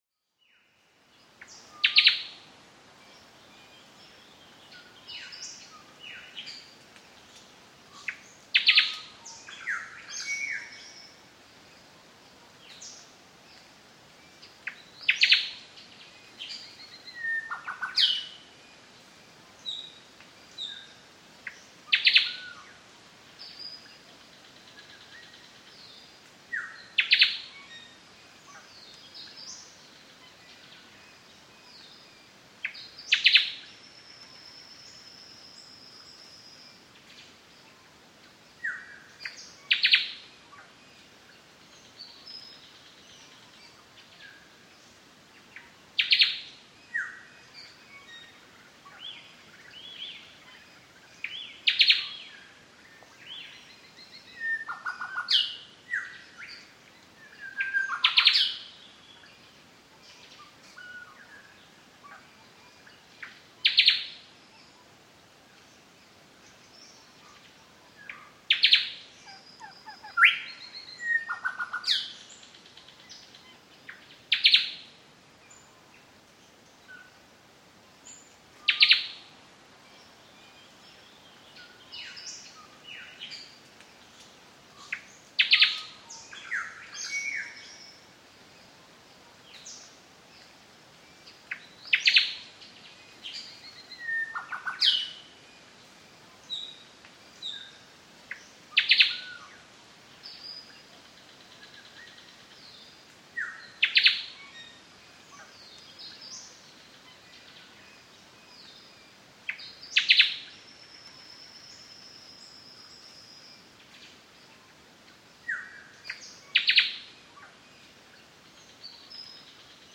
Звуки птиц в австралийском лесу днём